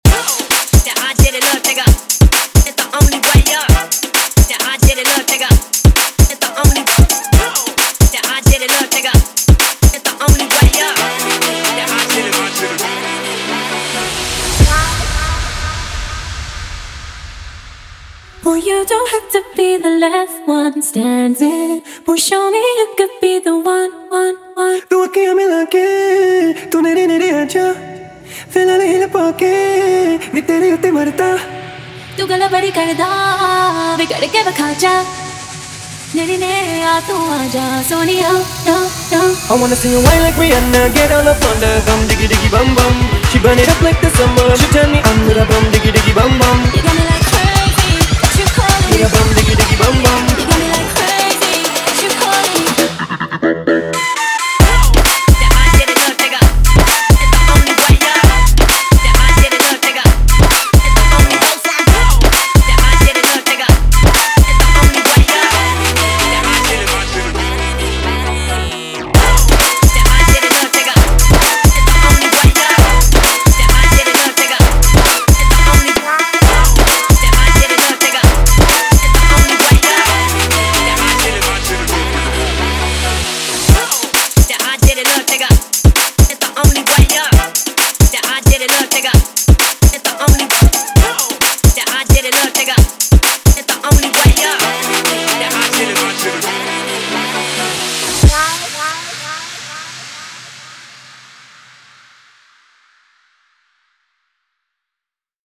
Category : Latest Dj Remix Song